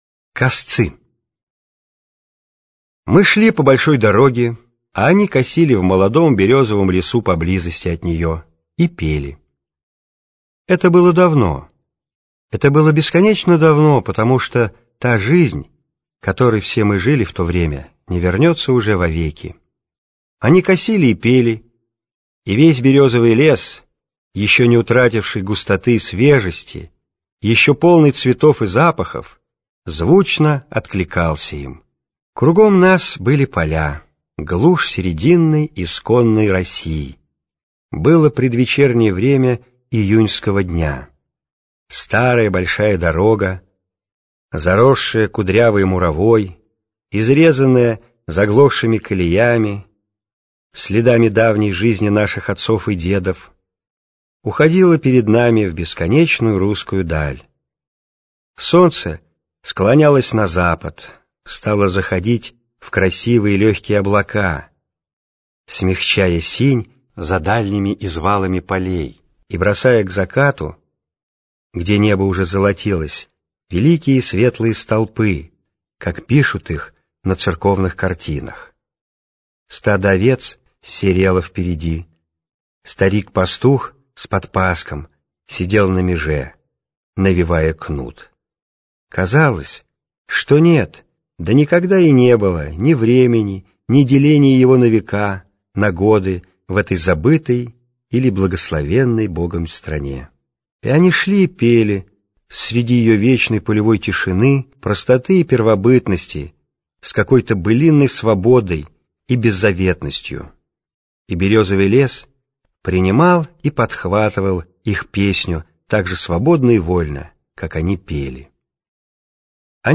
Косцы - аудио рассказ Бунина И.А. В рассказе автор вспоминает, как однажды в поле встретил рязанских косцов, которые пели песню.